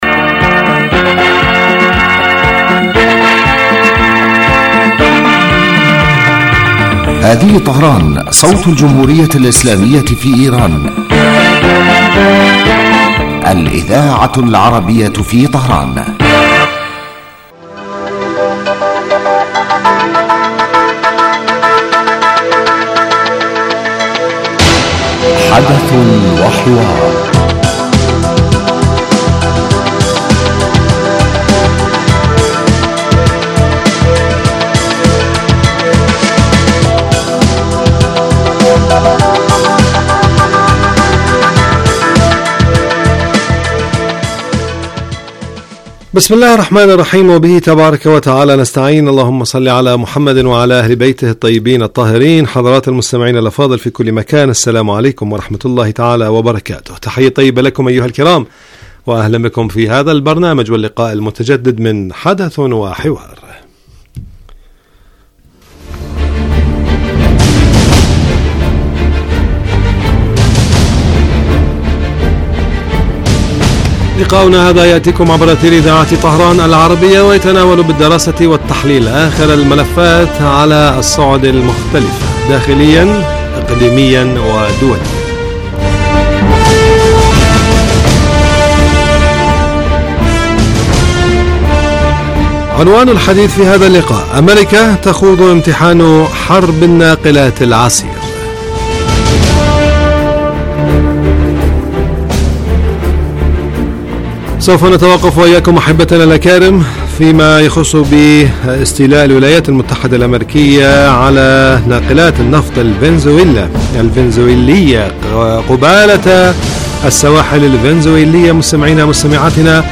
يبدأ البرنامج بمقدمة يتناول فيها المقدم الموضوع ثم يطرحه للنقاش من خلال تساؤلات يوجهها للخبير السياسي الضيف في الاستوديو.
ثم يتم تلقي مداخلات من المستمعين هاتفيا حول الرؤى التي يطرحها ضيف الاستوديو وخبير آخر يتم استقباله عبر الهاتف ويتناول الموضوع بصورة تحليلية.